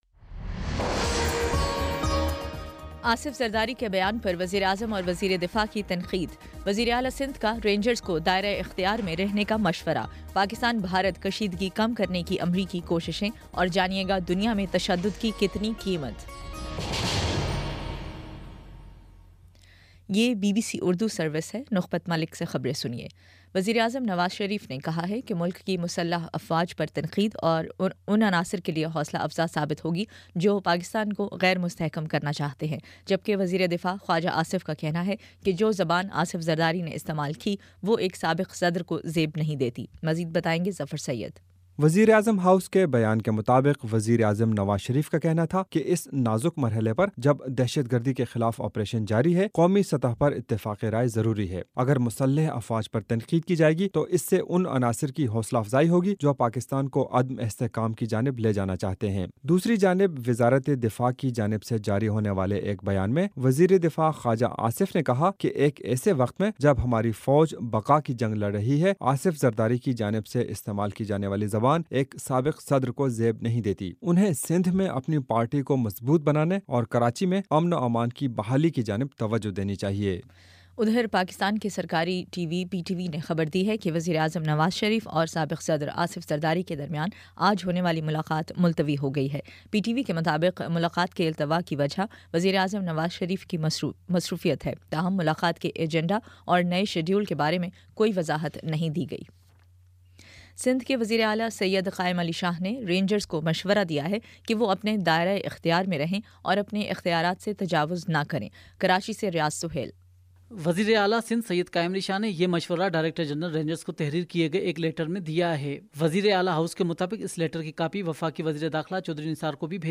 جون 17: شام پانچ بجے کا نیوز بُلیٹن